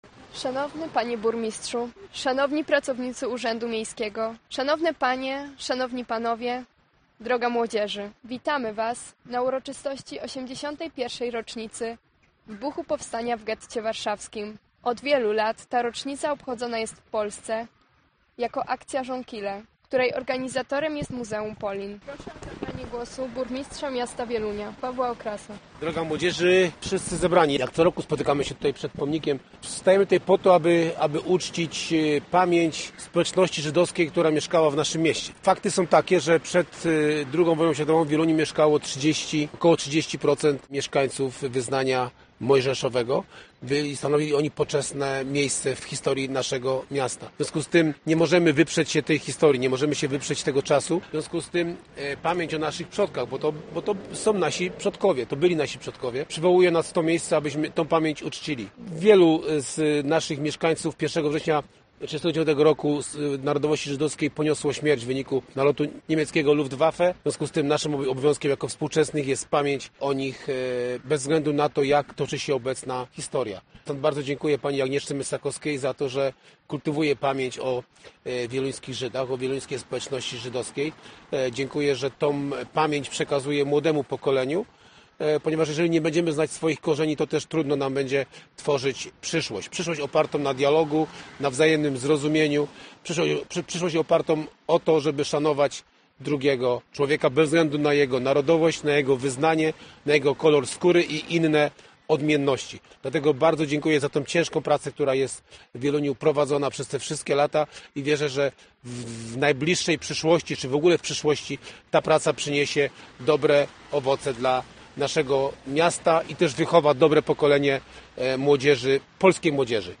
Dziś w 81. rocznicę wybuchu powstania w getcie warszawskim pod pomnikiem wieluńskich Żydów złożono kwiaty i zapalono znicze. Mówiono o tym jak ważny jest dialog, zwłaszcza w kontekście tego, co aktualnie dzieje się na Bliskim Wschodzie czy w Ukrainie.